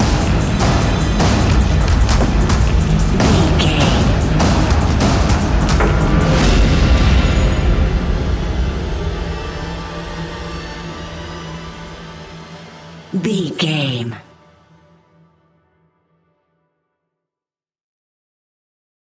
Fast paced
In-crescendo
Ionian/Major
industrial
dark ambient
EBM
synths